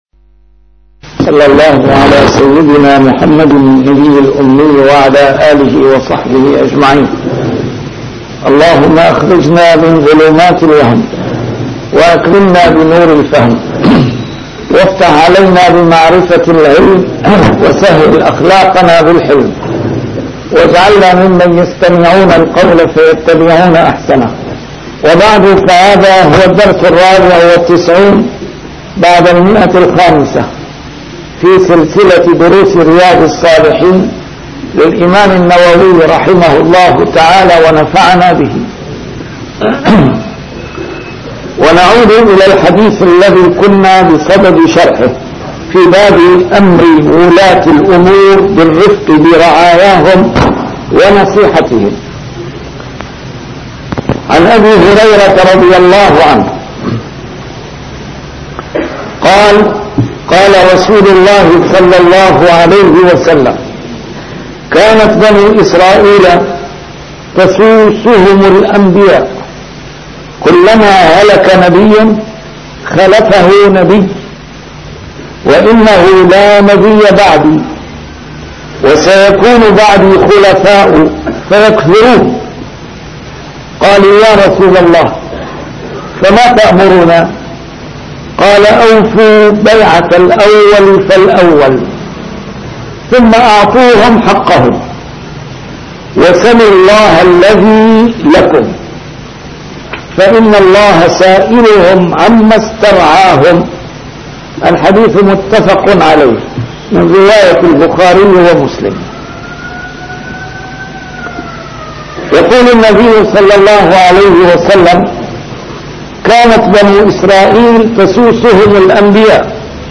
A MARTYR SCHOLAR: IMAM MUHAMMAD SAEED RAMADAN AL-BOUTI - الدروس العلمية - شرح كتاب رياض الصالحين - 594- شرح رياض الصالحين: أمر الولاة